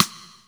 808-Rim4.wav